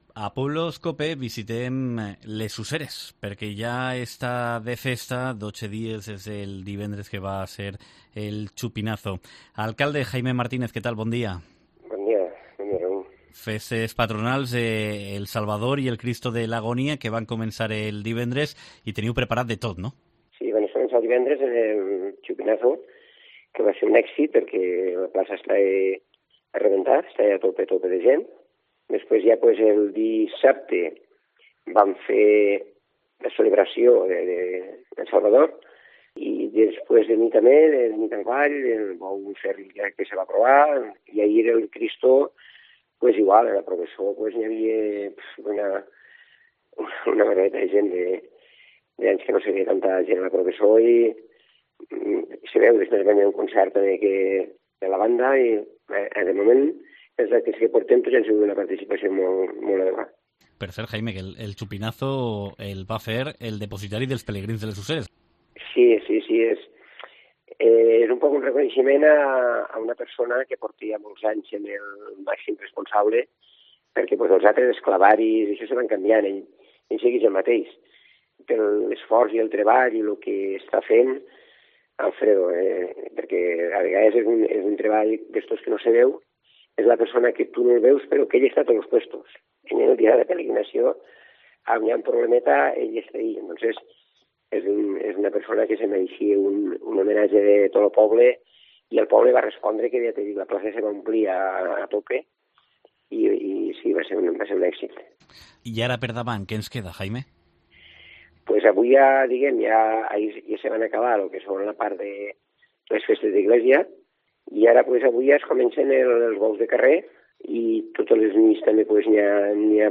Entrevista
Les Useres celebra las fiestas de El Salvador y el Santísimo Cristo de la Agonía con una completa agenda hasta el 15 de agosto y que presenta en COPE el alcalde, Jaime Martínez